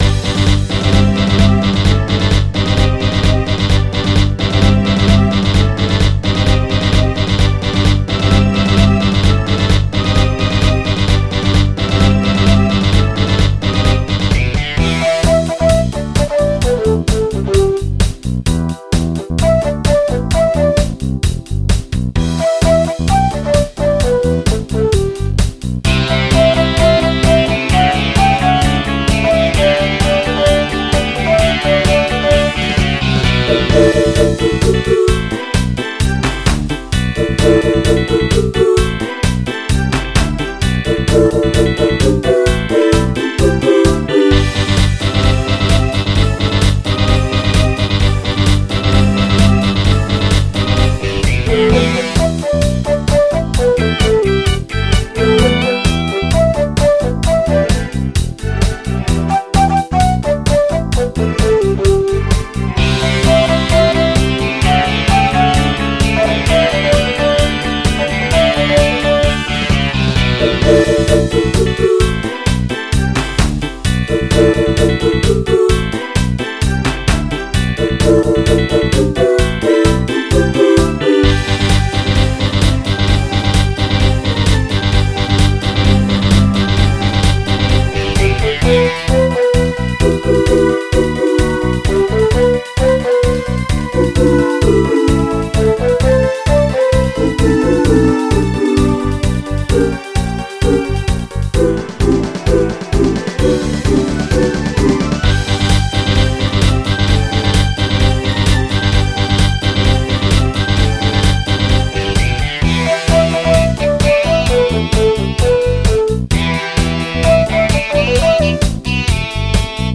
MIDI 70.52 KB MP3
i think its a dancey kinda rock song from the 70s